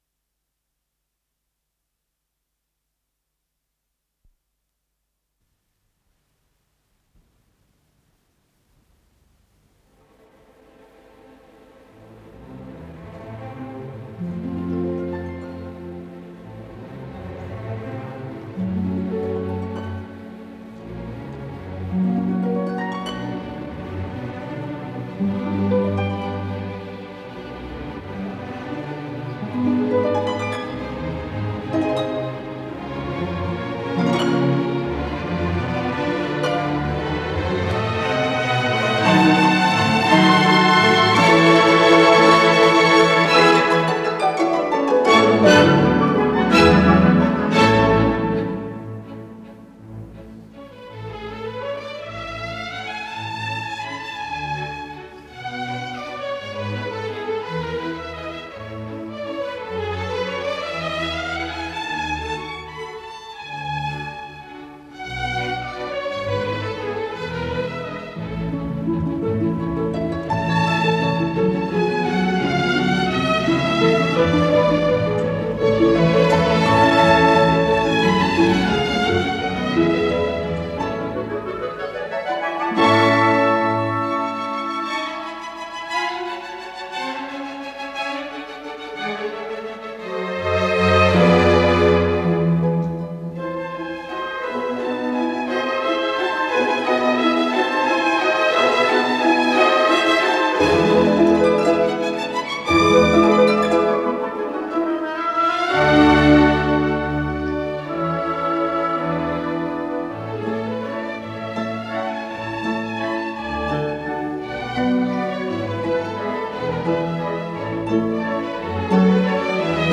с профессиональной магнитной ленты
ИсполнителиБольшой симфонический оркестр Всесоюзного радио и Центрального телевидения
Художественный руководитель и дирижёр - Геннадий Рождественский
Скорость ленты38 см/с